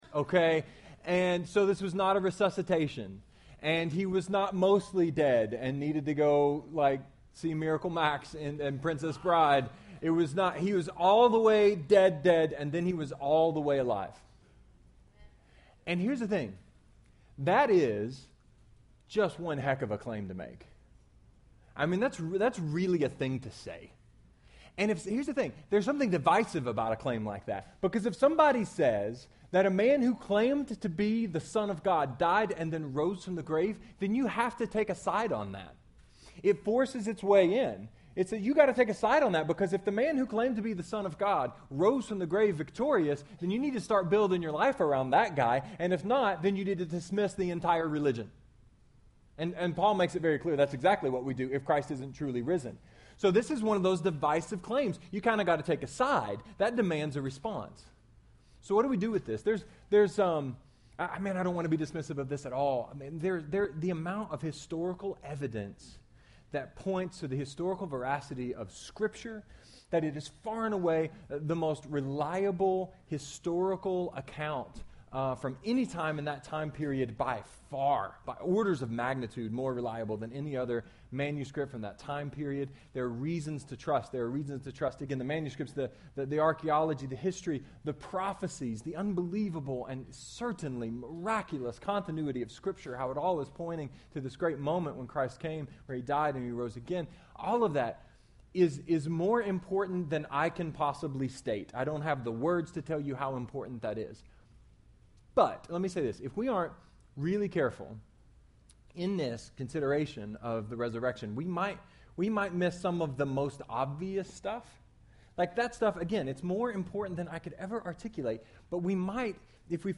A sermon about resurrection, rational thought and hope.